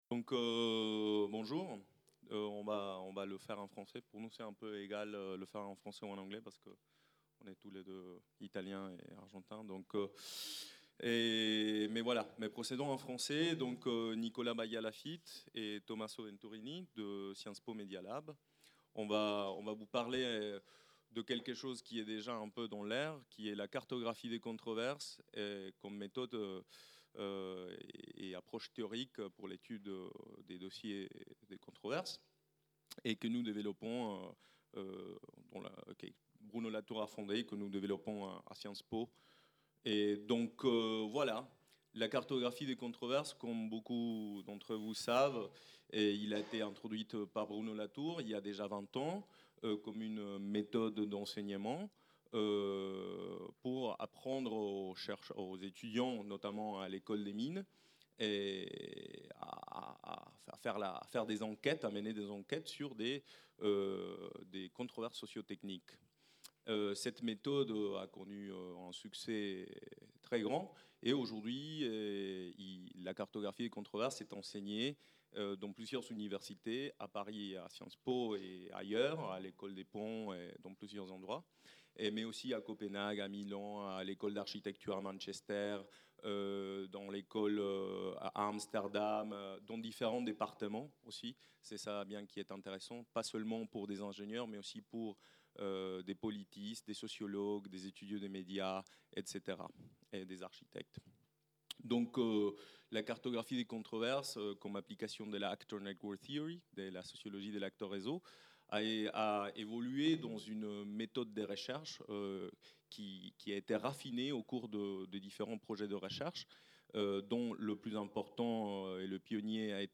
About 55 persons participated, on March 7 and 8 2013 in Meudon, to the international conference